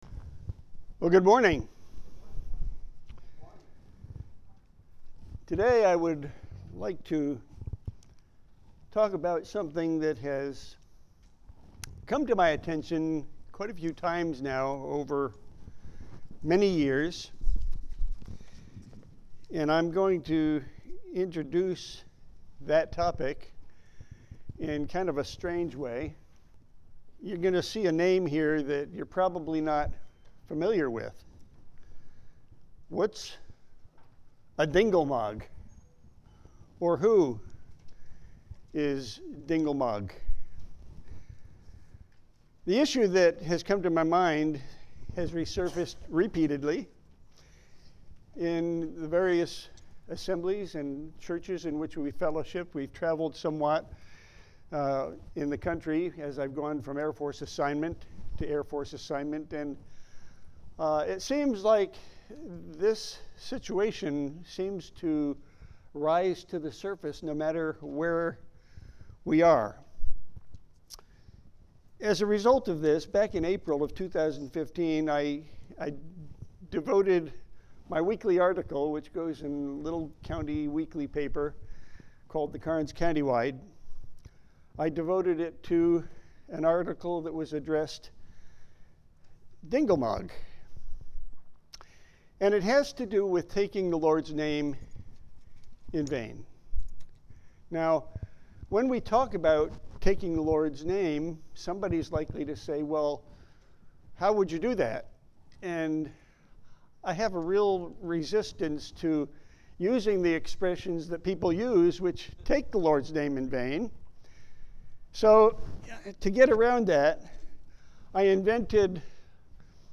Handouts showing Scriptures discussed in sermon audio